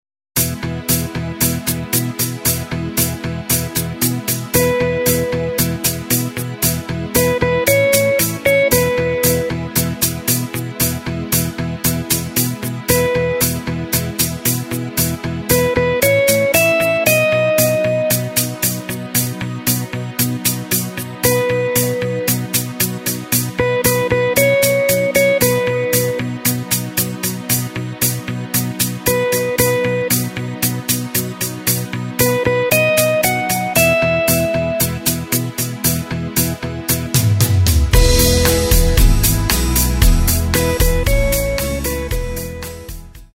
Tempo:         115.00
Tonart:            E
Schlager aus dem Jahr 1989!